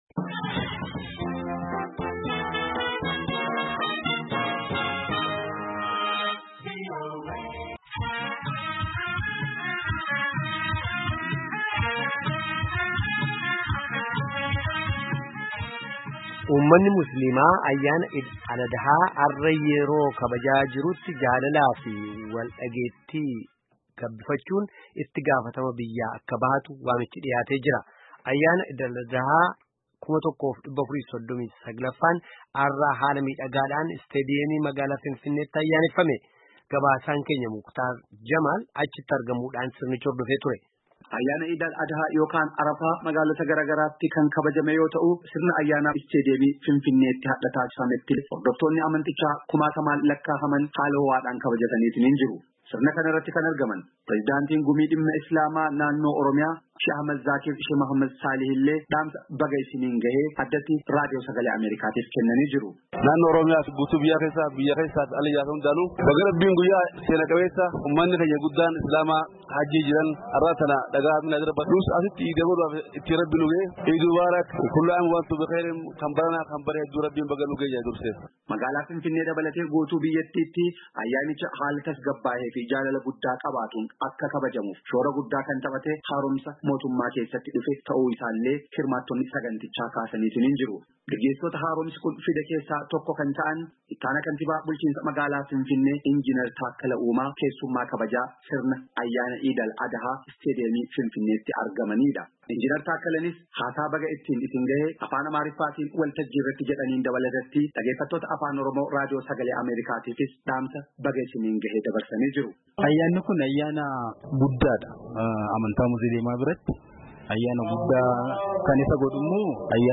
Ayyaanni Id Al Adahaa 1439ffaan hardha haala midhagaadhaan Istaadiyeemii magaalaa Finfinneetti Ayyaaneeffame.